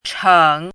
chěng
cheng3.mp3